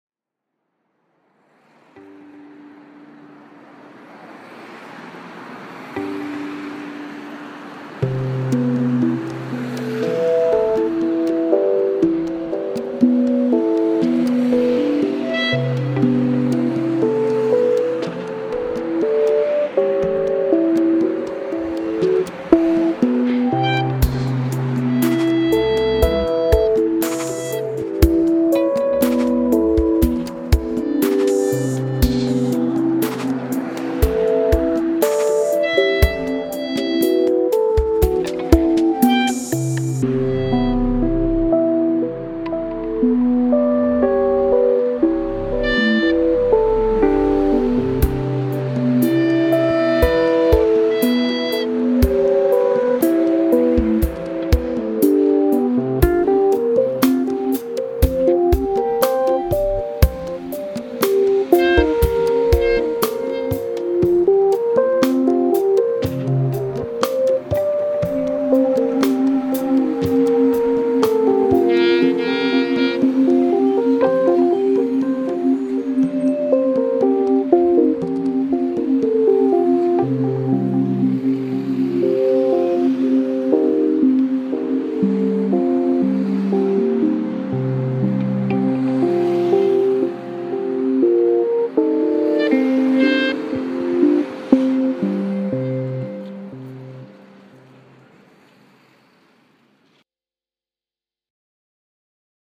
June 2017. 20 tracks of instrumental music